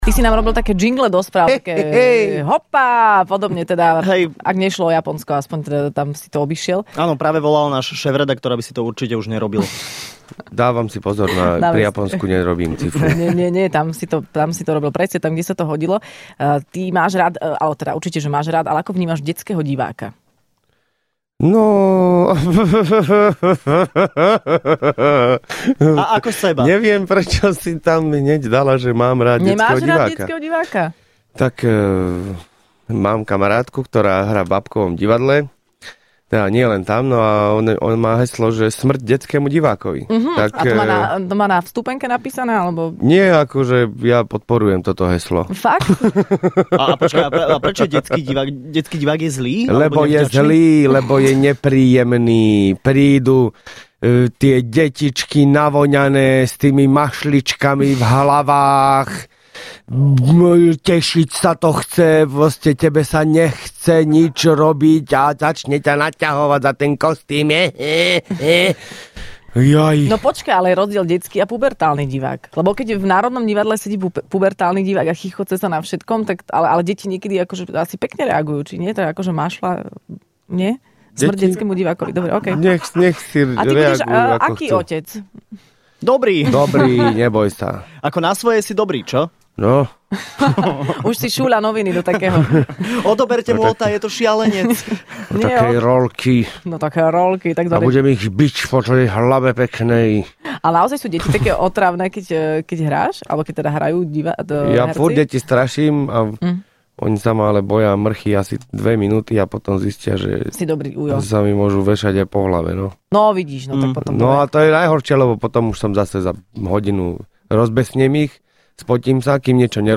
Hosťom v Rannej šou bol absolútny víťaz ocenenia OTO Lukáš Latinák.